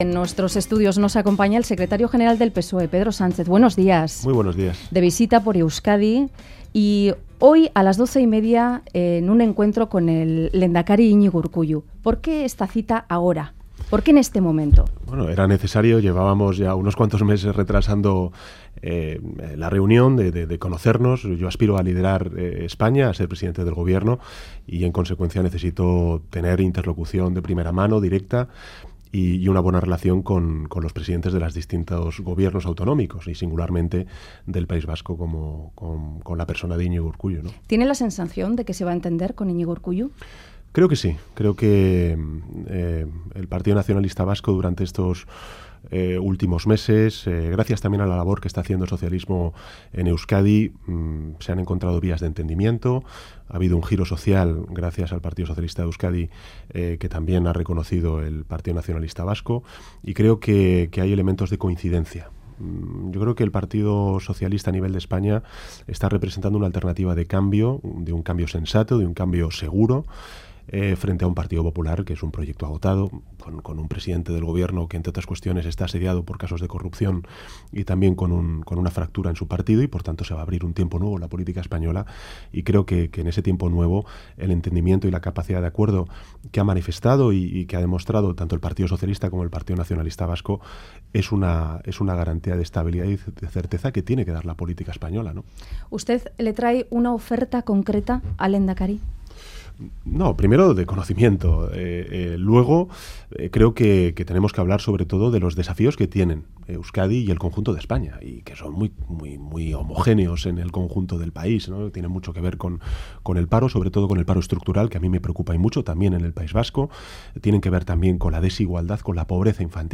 Radio Euskadi BOULEVARD 'El entendimiento entre PNV y PSE es una garantía de estabilidad' Última actualización: 17/07/2015 09:46 (UTC+2) En entrevista al Boulevard de Radio Euskadi, el secretario general del PSOE, Pedro Sánchez, ha valorado el actual entendimiento entre PNV y PSE y ha afirmado que es una garantía de estabilidad y certeza. Ha reiterado su propuesta de reforma constitucional para avanzar hacia un Estado Federal en el que todos estén unidos en la diversidad, ha subrayado que no habrá ningún retroceso en el autogobierno vasco y ha advertido que no aceptarán la independencia de Cataluña.